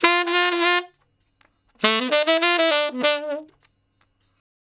066 Ten Sax Straight (D) 04.wav